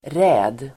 Ladda ner uttalet
räd substantiv, raid Uttal: [rä:d] Variantform: även raid Böjningar: räden, räder Synonymer: razzia Definition: överraskande anfall (bakom fiendens linjer) Sammansättningar: stöldräd (raid by thieves, burglary)